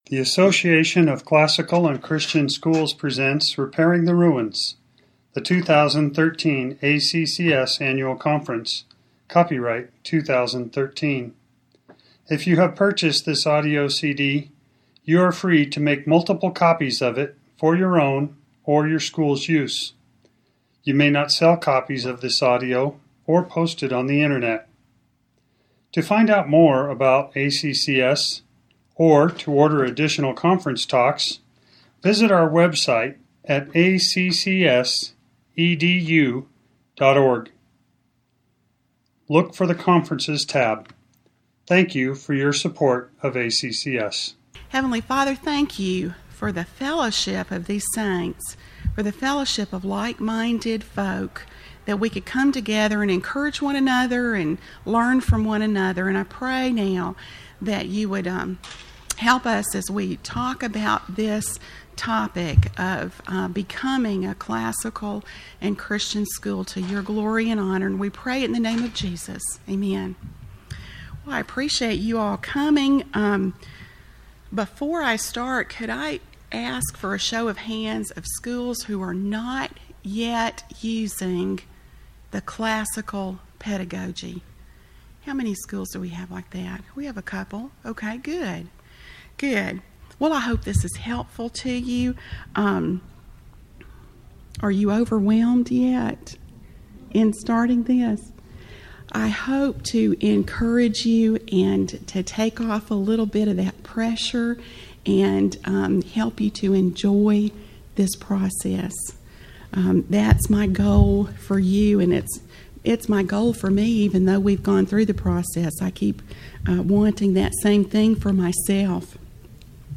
2014 Foundations Talk | 0:54:17 | Leadership & Strategic